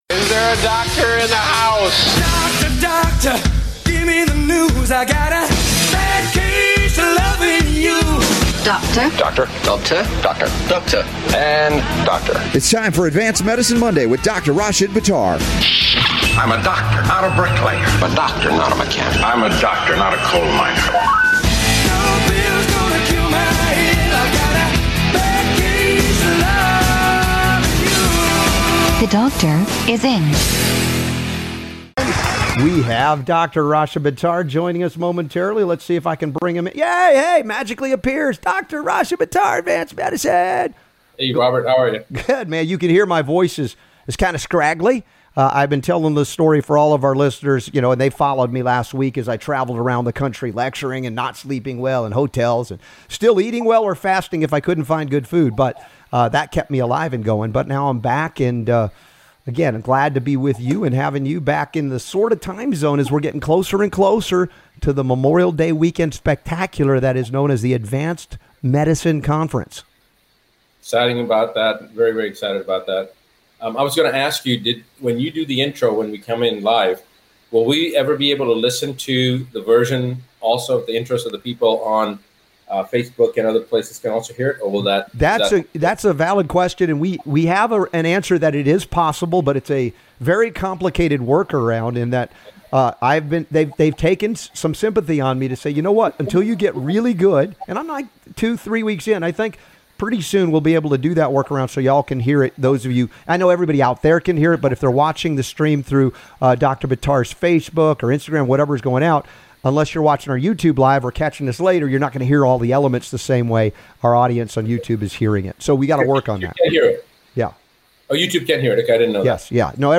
Advanced Medicine Radio Show | 4-1-2019 Get ready to learn things not traditionally taught to medical doctors!